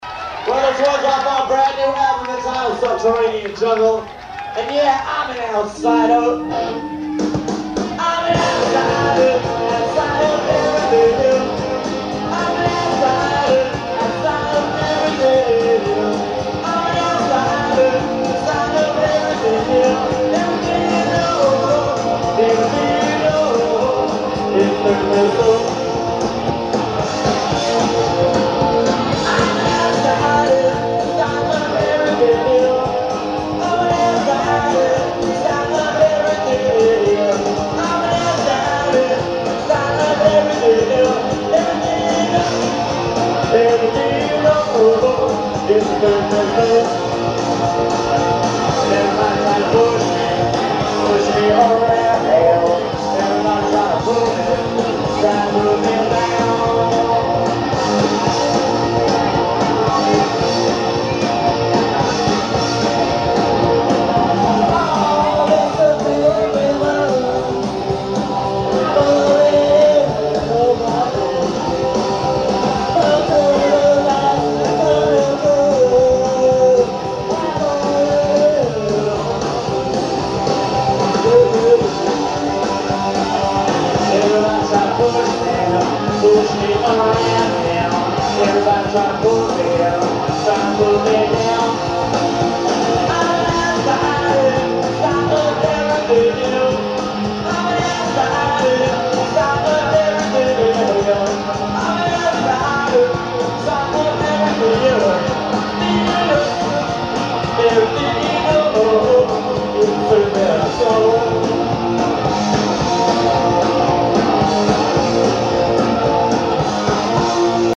Ripley Music Hall-Philadelphia 3-16-83